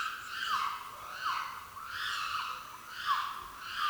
Animal Sounds
Chimpanzee 1190